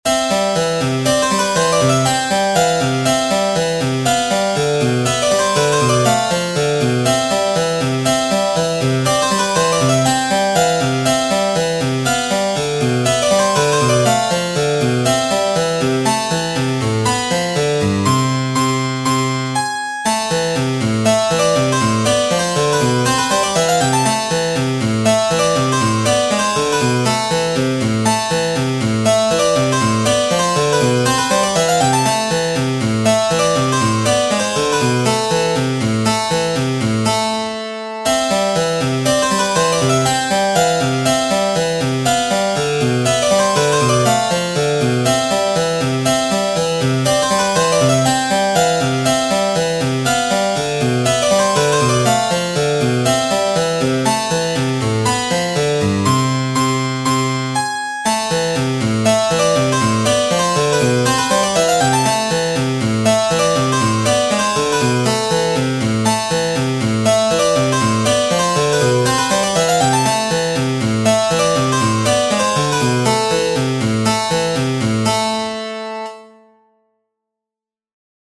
Piano #125 - Piano Music, Solo Keyboard